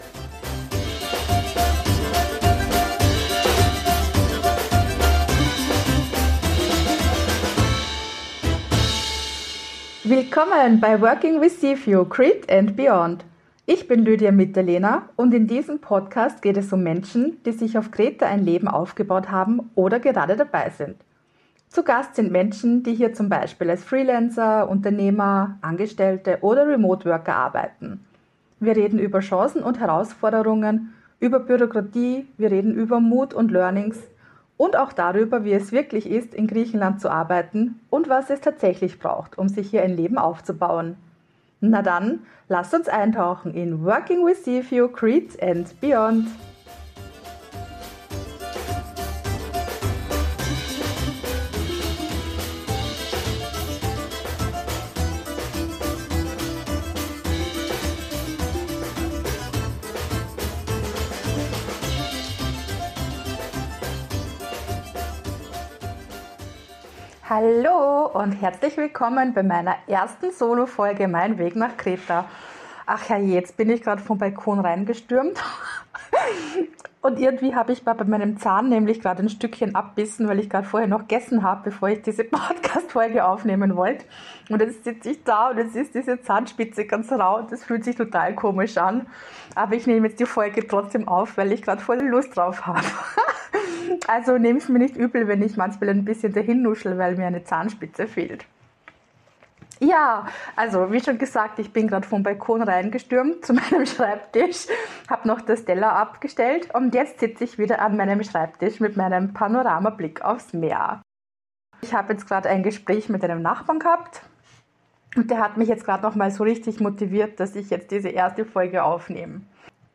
Beschreibung vor 2 Wochen In meiner ersten Solo-Folge erzähle ich dir kurz und bündig, wie mein Weg von Österreich nach Kreta ausgesehen hat. Ich nehme dich mit in meine Sehnsucht nach dem Meer, spreche über Covid als Wendepunkt und über die Entscheidung, Wien für eine Zeit hinter mir zu lassen und meinen Lebensmittelpunkt nach Griechenland zu verlegen. Du erfährst von meinen Zweifeln, meiner Absicherungsstrategie und davon, wie mein Start hier auf Kreta wirklich war.